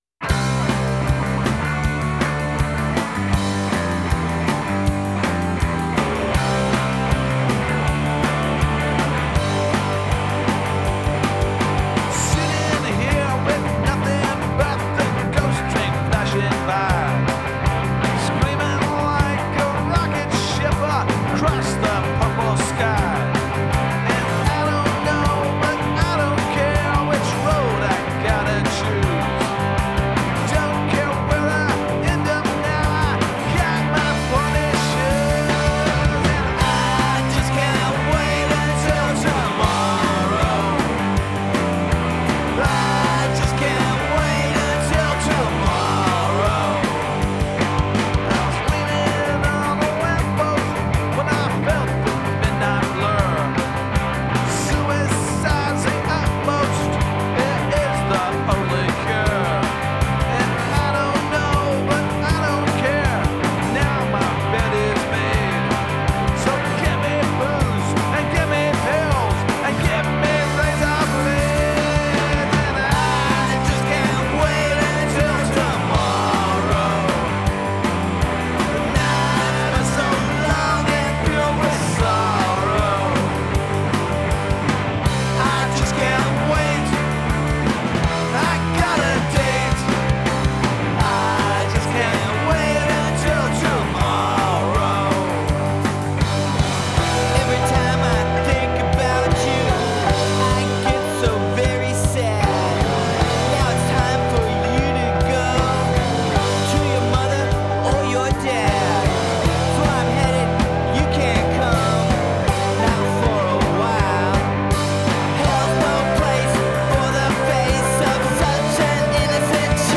Soundtrack, Comedy, Drama